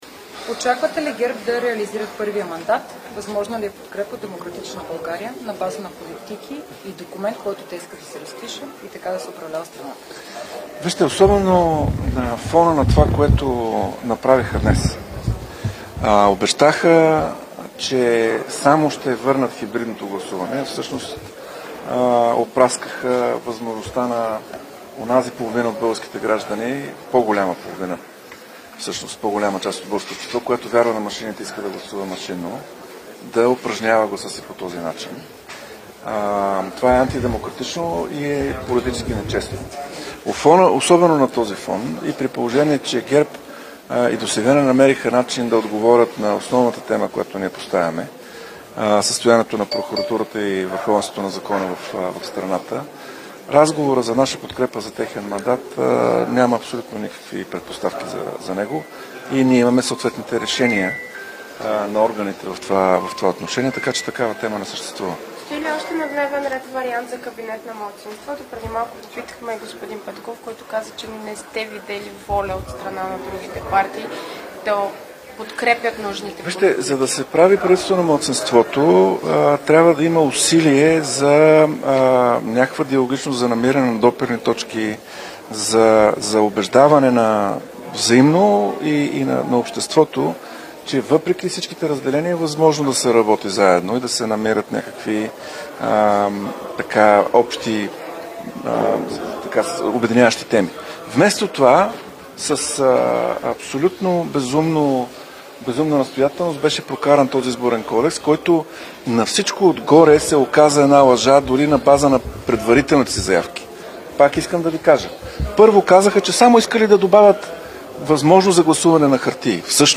12.40  -   Брифинг на съпредседателя на ПП Кирил Петков. - директно от мястото на събитието (Народното събрание)